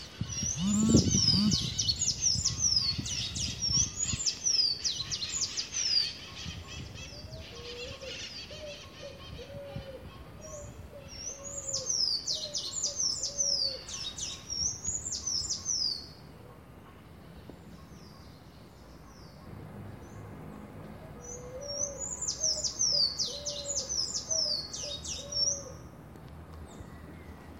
Celestino Común (Thraupis sayaca)
Nombre en inglés: Sayaca Tanager
Fase de la vida: Adulto
Localidad o área protegida: Gran Buenos Aires Norte
Condición: Silvestre
Certeza: Observada, Vocalización Grabada